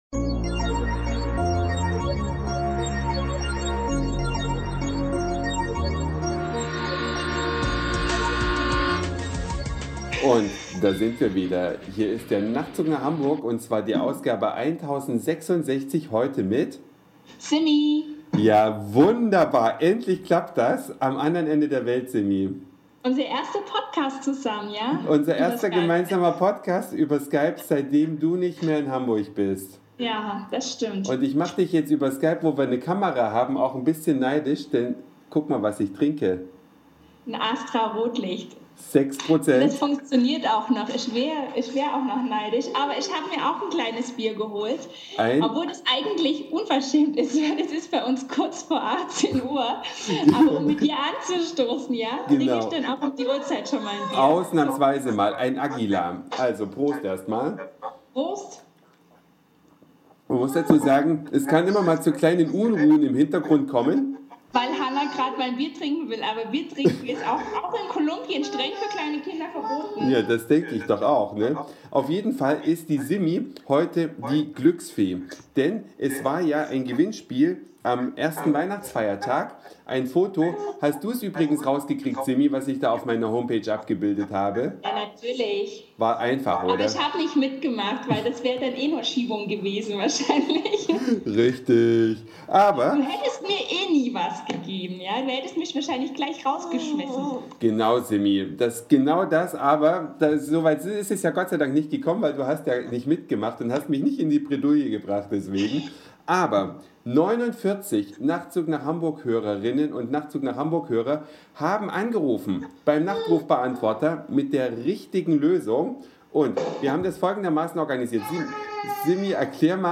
Über Skype bin ich mit der Glücksfee verbunden und ihr Töchterchen hört man ganz gut im Hintergrund.